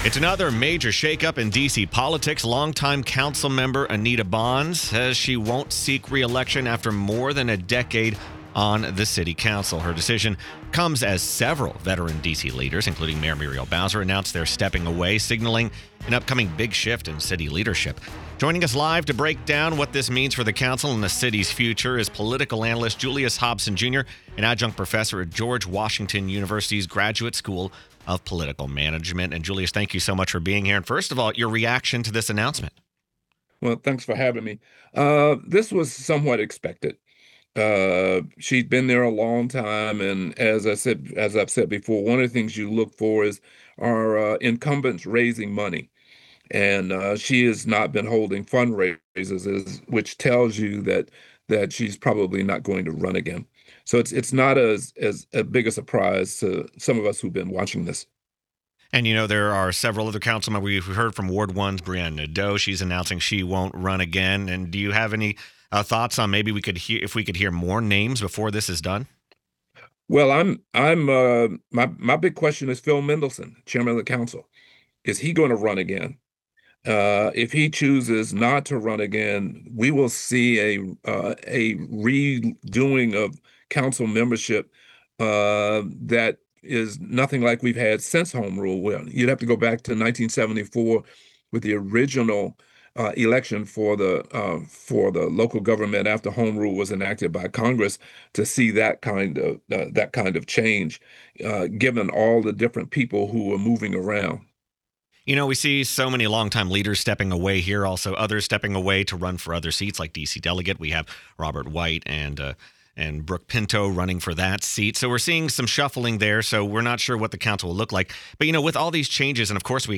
political analyst